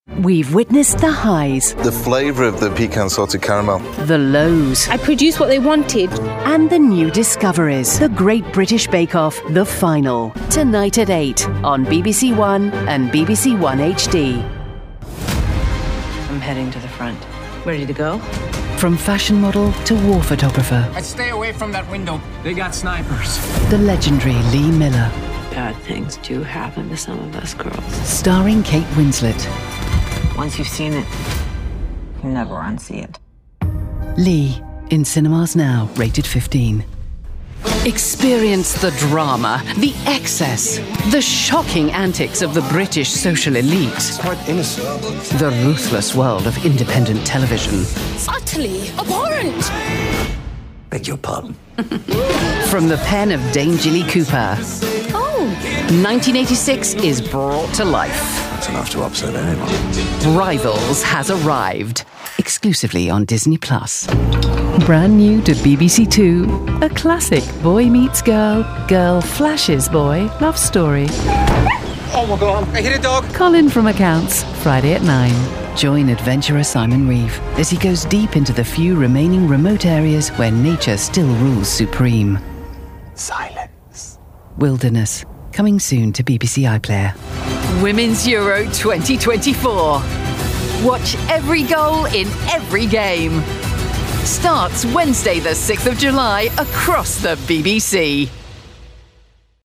Promo Showreel
Straight
Promo, Showreel